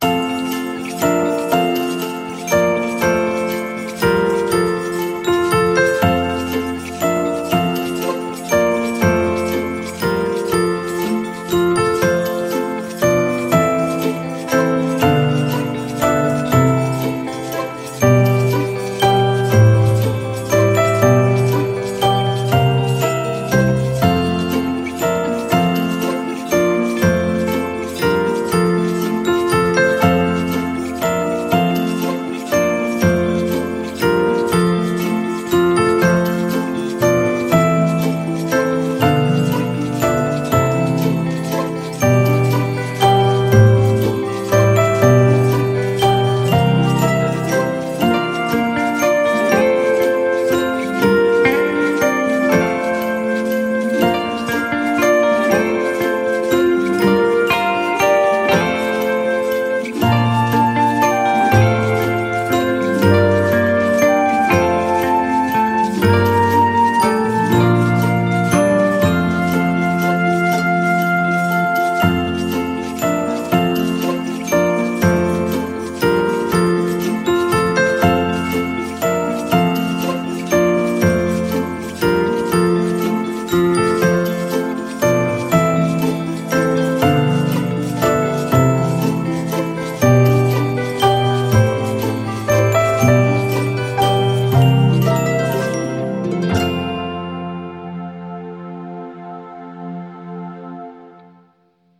Un choix de 6 musiques d’ambiance vous est aussi offert.